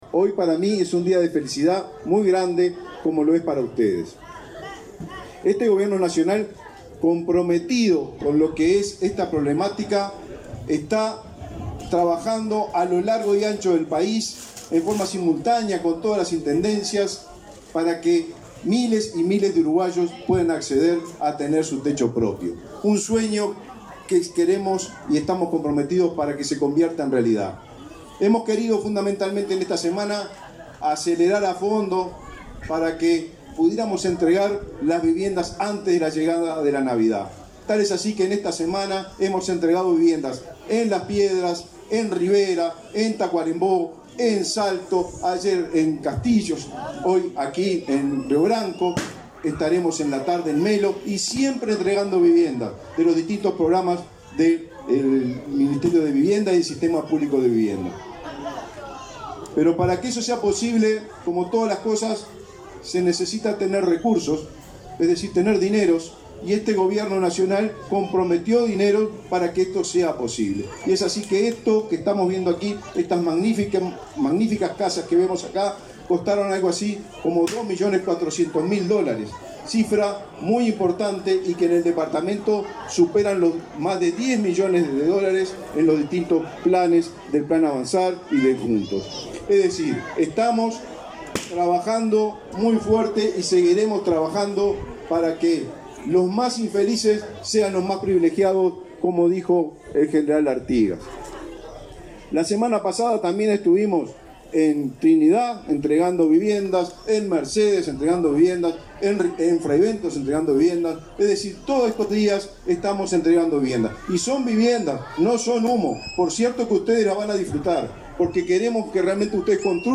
Palabras del ministro de Vivienda, Raúl Lozano
Este viernes 22 en Cerro Largo, el ministro de Vivienda, Raúl Lozano, participó de la inauguración de viviendas del programa Juntos en la localidad de